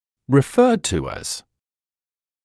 [rɪ’fɜːd tu æz][ри’фёːд ту эз]называемый как